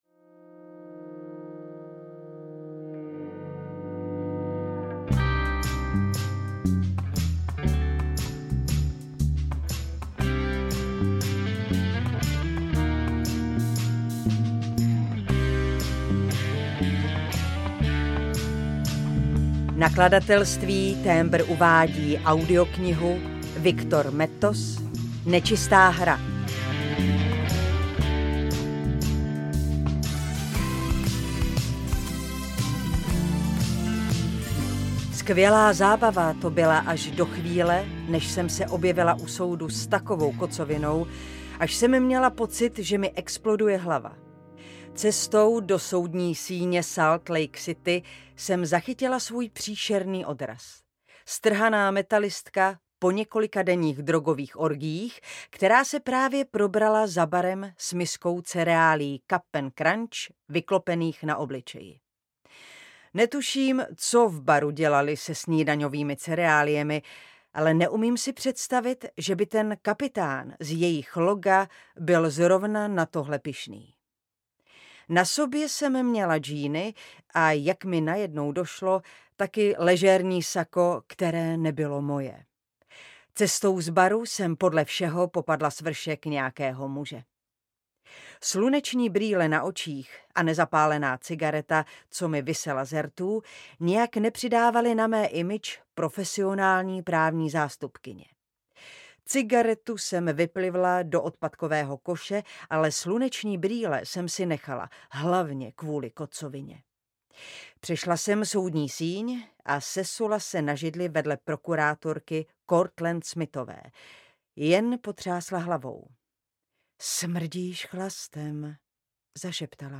Nečistá hra audiokniha
Ukázka z knihy